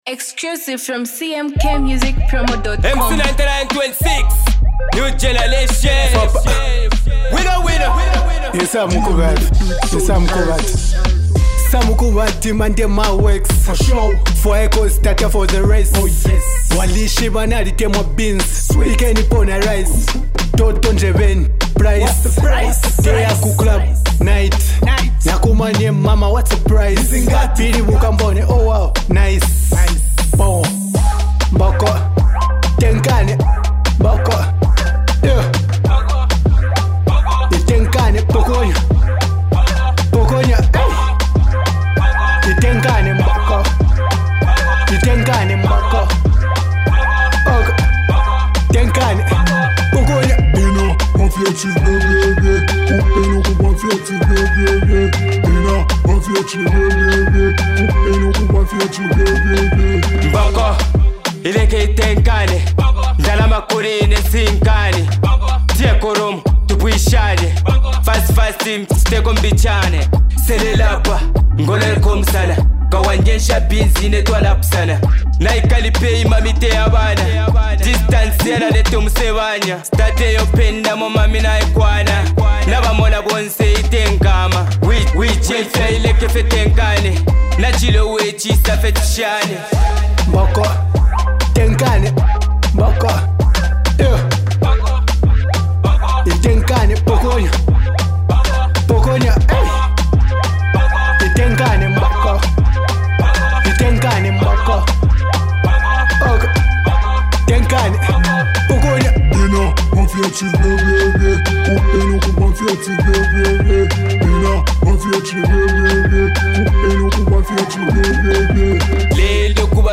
ultimate December dance anthem